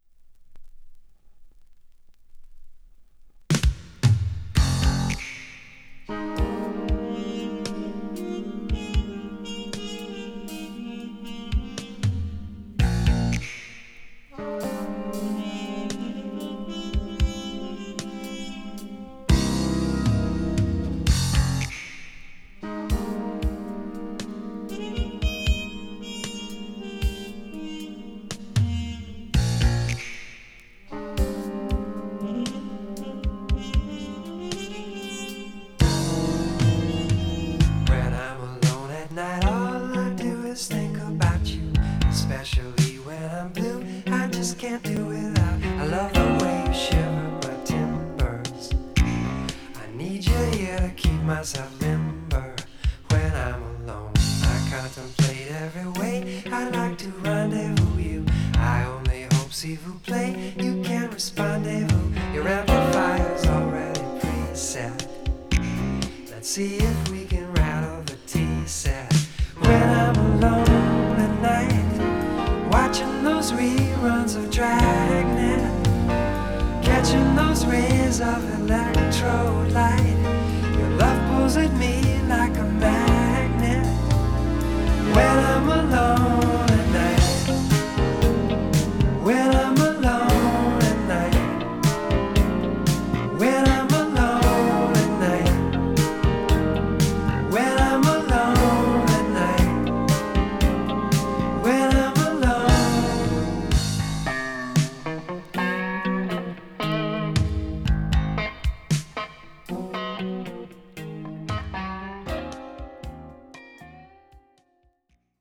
Du har förskjuten ljudbild åt vänster.
Det låter också rätt "försiktigt".